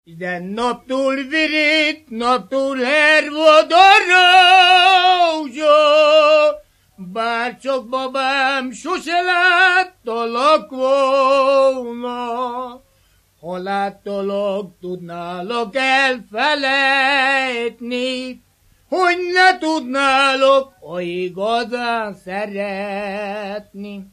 Erdély - Szilágy vm. - Szilágybagos
Műfaj: Lassú csárdás
Stílus: 3. Pszalmodizáló stílusú dallamok
Kadencia: X (X) X 1